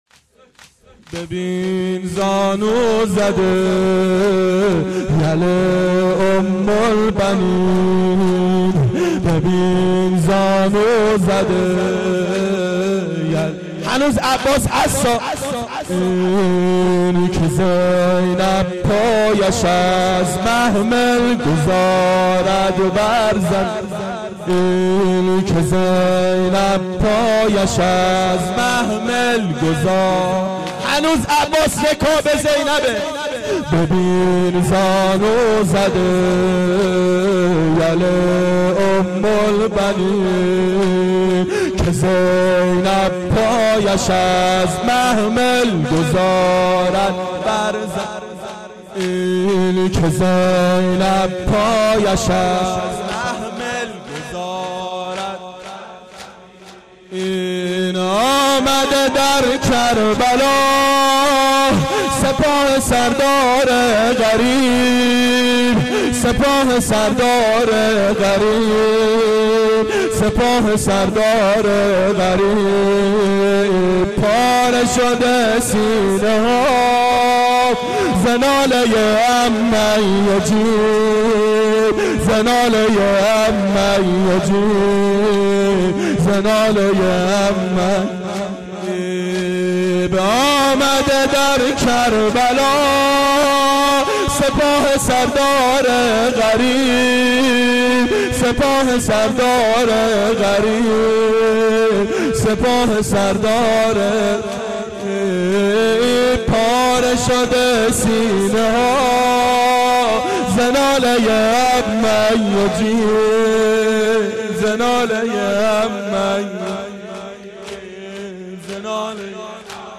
شب سوم محرم 88 گلزار شهدای شهر اژیه